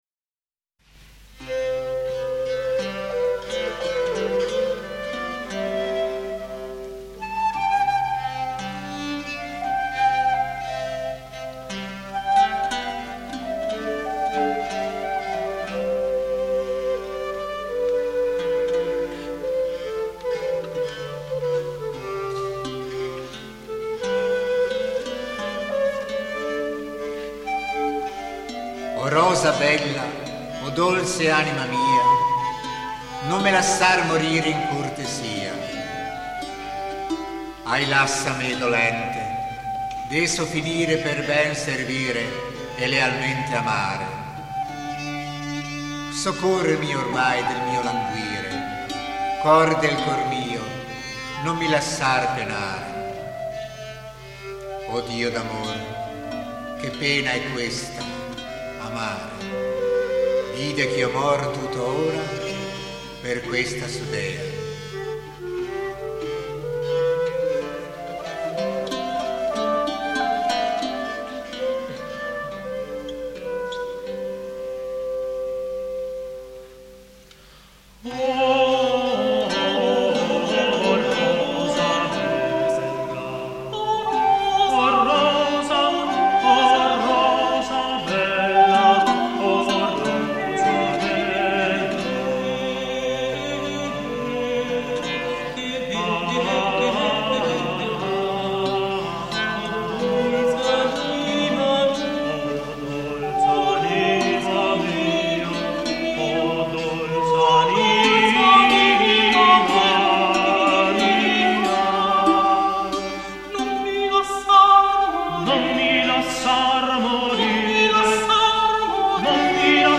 che si dedica alla ricerca nel campo delle musiche medioevali, rinascimentali e barocche, usando ricostruzioni di strumenti dell’epoca.